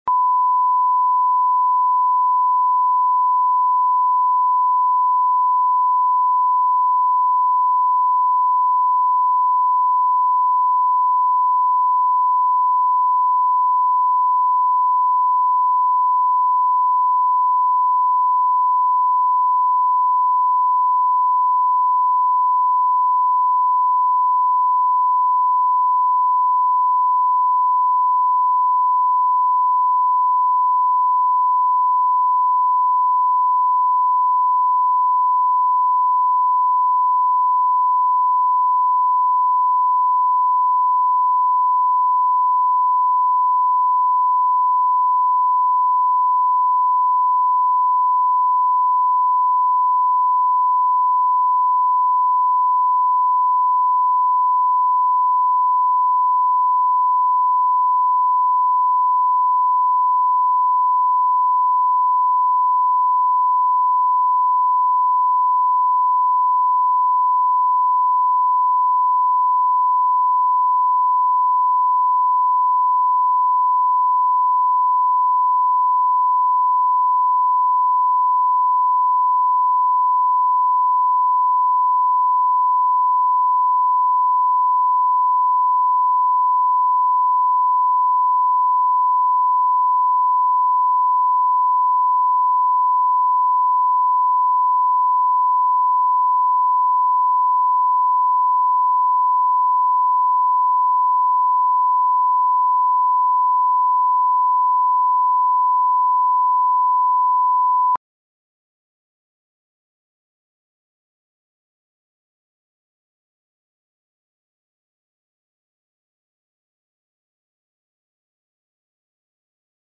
Аудиокнига Только один недостаток | Библиотека аудиокниг